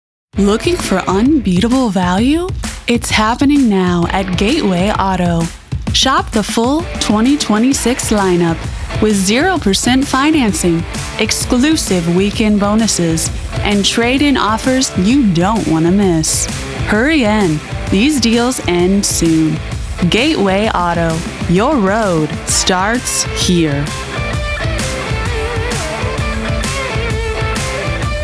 Automotive
English - Midwestern U.S. English
Operate a broadcast-quality home studio featuring a Shure SM7B microphone and Universal Audio Apollo Twin interface, delivering clean WAV audio with quick turnaround and professional editing.
My voice has a calm, reassuring quality that helps listeners feel both informed and encouraged.